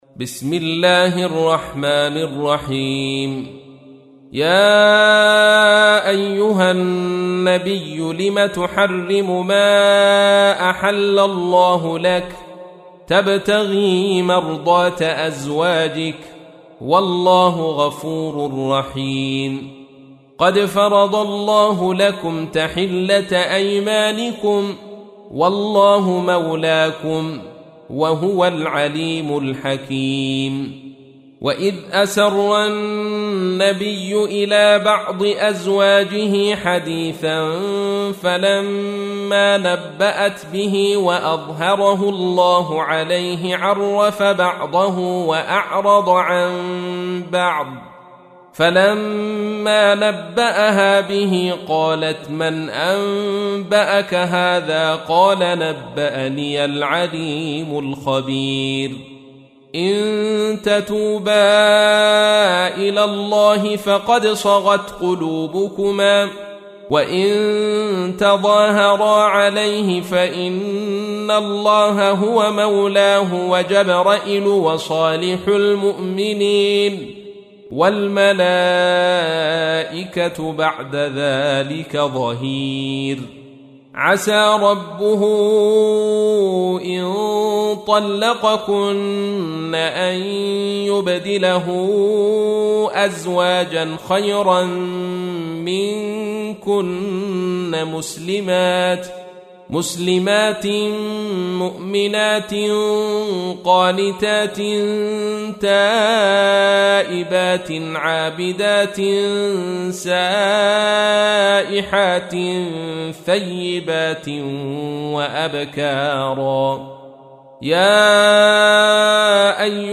تحميل : 66. سورة التحريم / القارئ عبد الرشيد صوفي / القرآن الكريم / موقع يا حسين